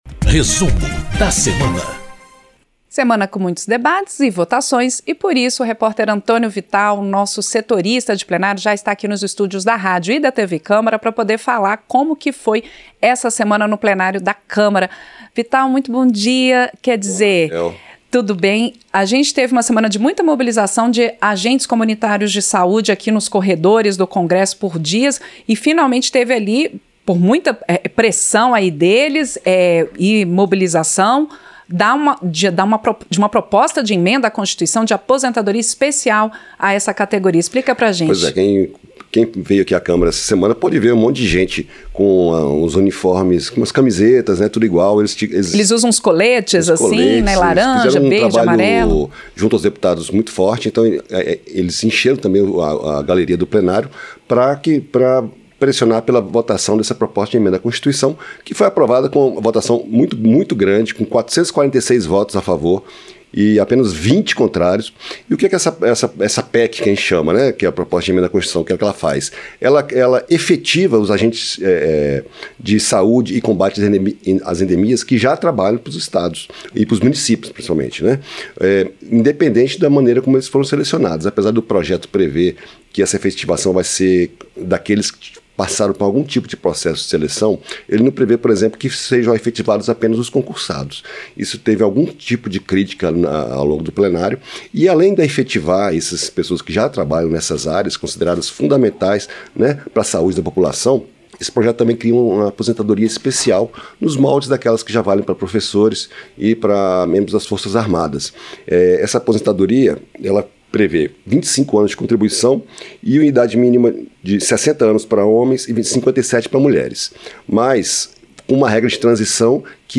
Apresentação